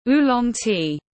Trà ô long tiếng anh gọi là oolong tea, phiên âm tiếng anh đọc là /ˈuː.lɒŋ/